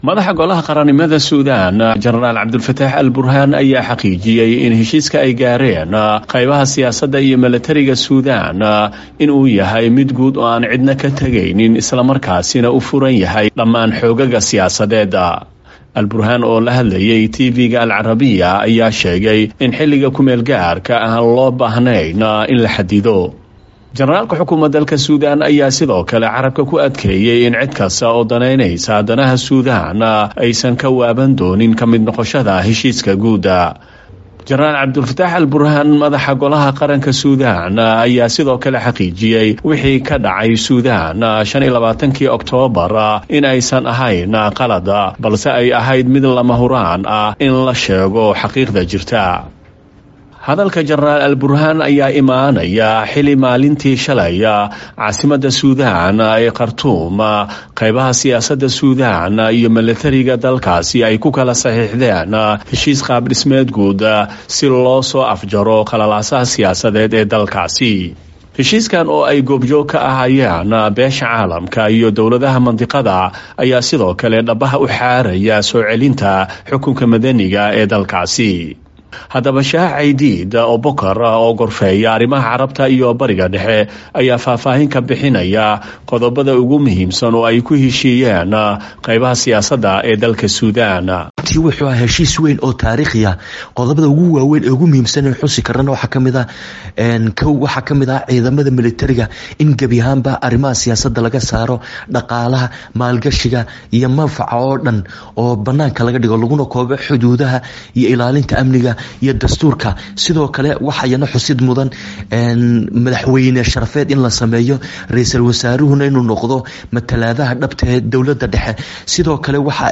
Warbixinta Heshiiska Sudan